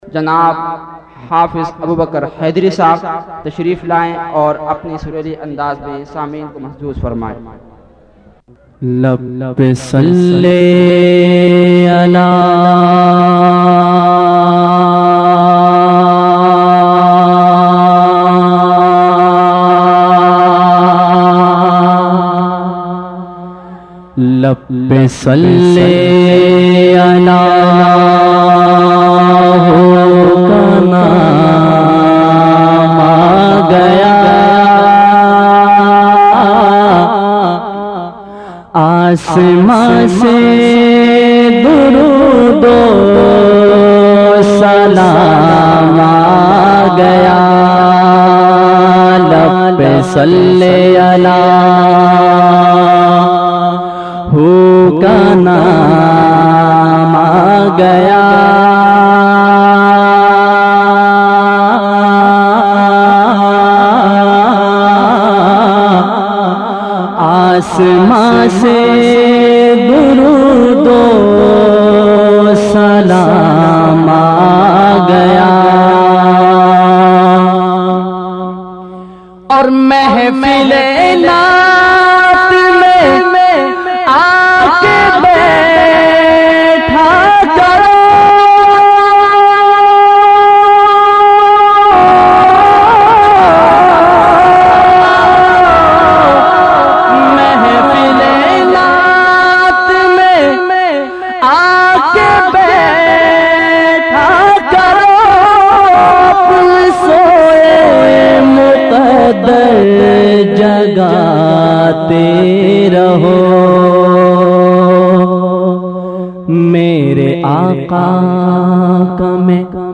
His amazing accent draws in his followers.